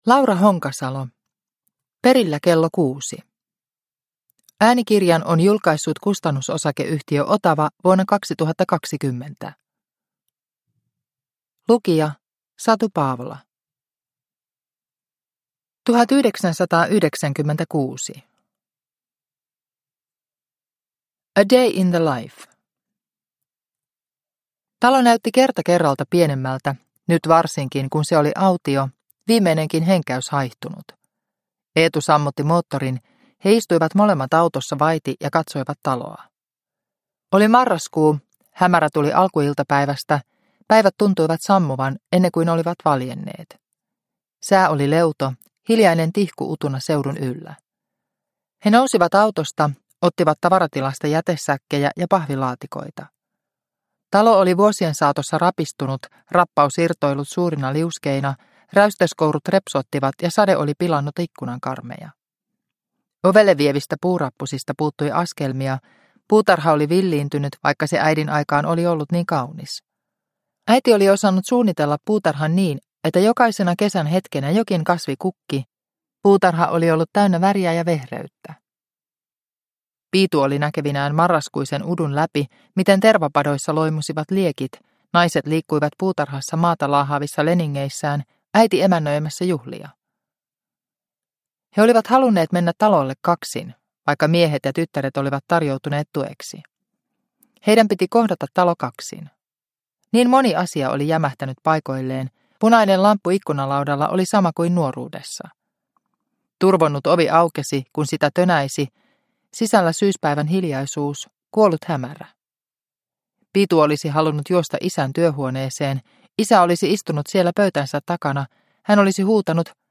Perillä kello kuusi – Ljudbok – Laddas ner